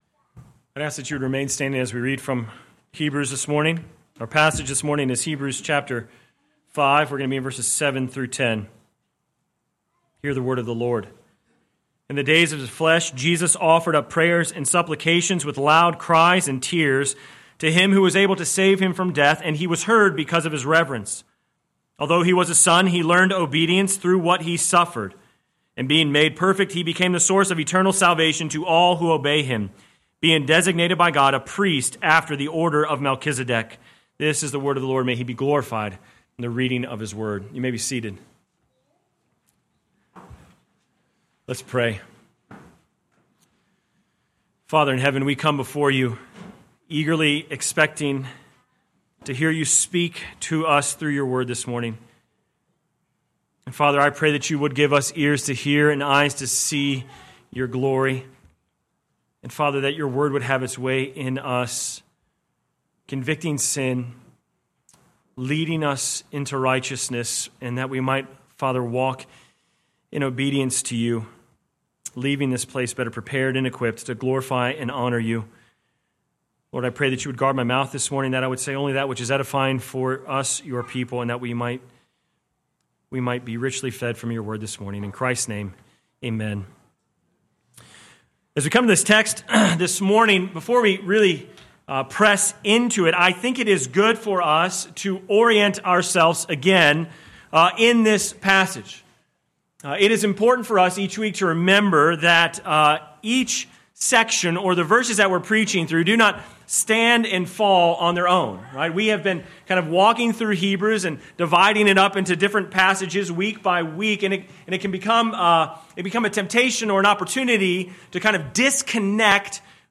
Sermon Text: Hebrews 5:7-10 First Reading: Deuteronomy 32:44-47 Second Reading: Romans 8:1-11